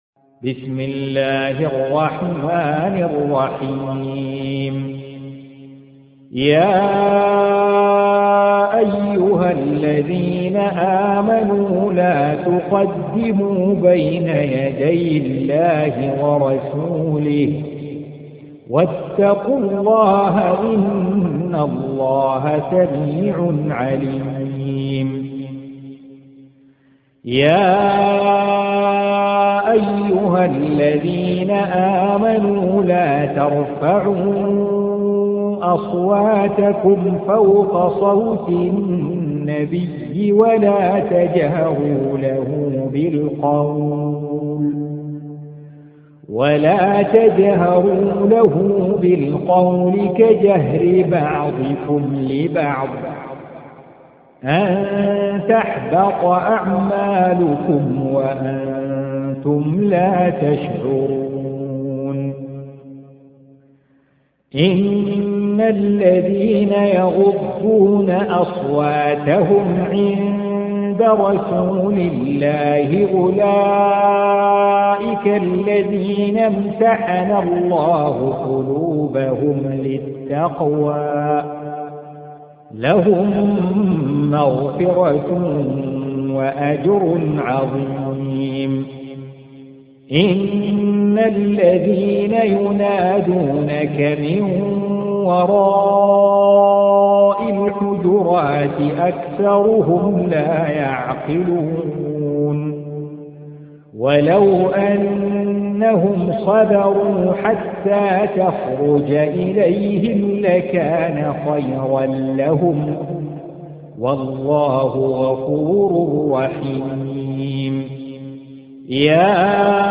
Surah Sequence تتابع السورة Download Surah حمّل السورة Reciting Murattalah Audio for 49. Surah Al-Hujur�t سورة الحجرات N.B *Surah Includes Al-Basmalah Reciters Sequents تتابع التلاوات Reciters Repeats تكرار التلاوات